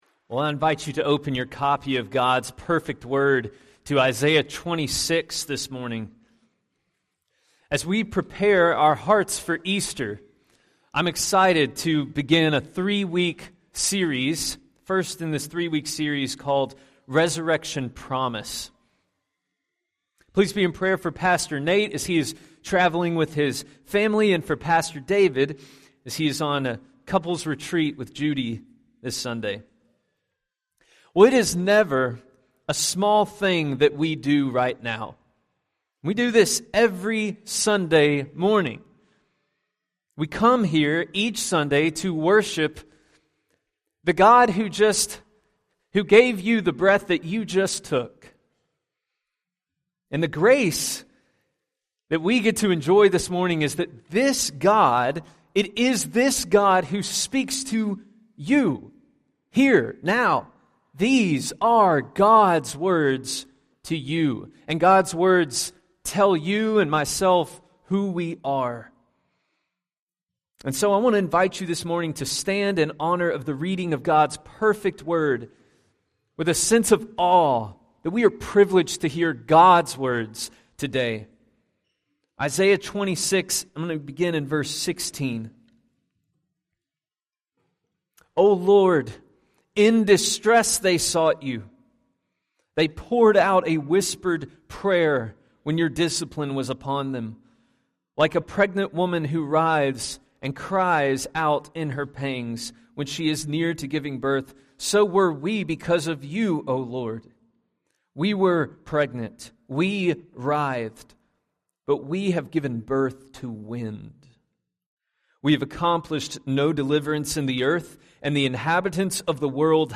In today's sermon from our Easter series we see that darkness of death is only fully and finally defeated in the resurrection of Christ.